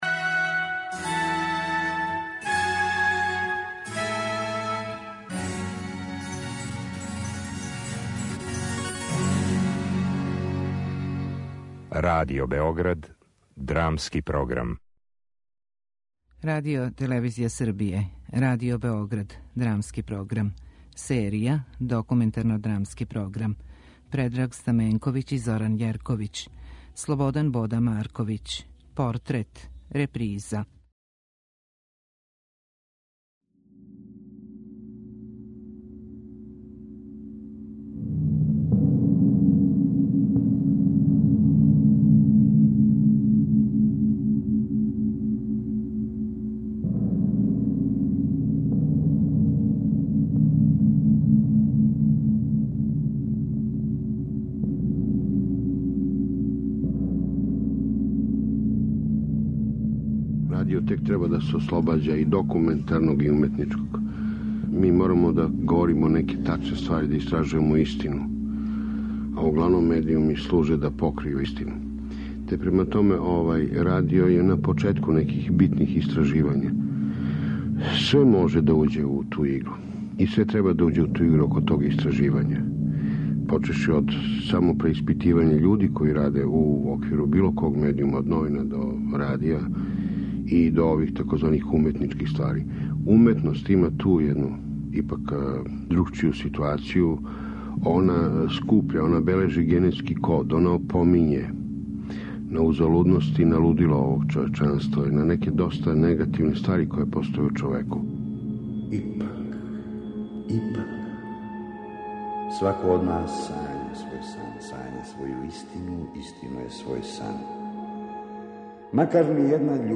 Документарно-драмски програм